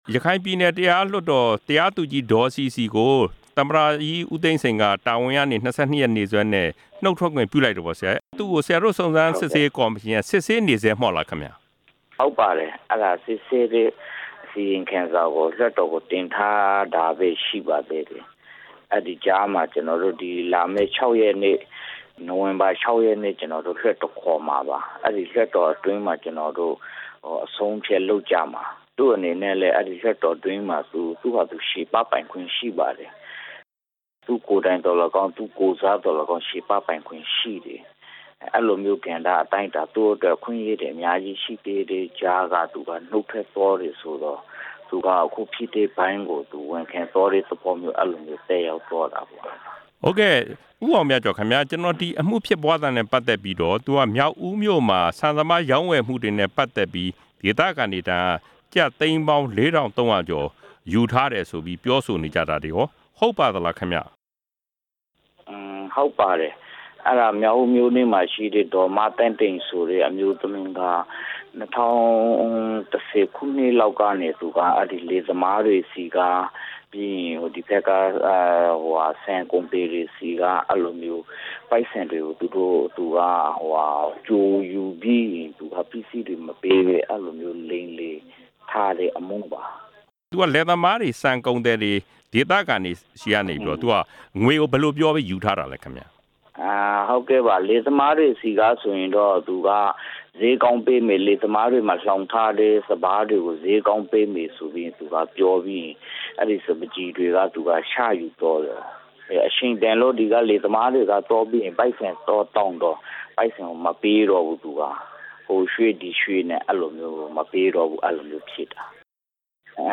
လွှတ်တော်အမတ် ဦးအောင်မြကျော်နဲ့ မေးမြန်းချက်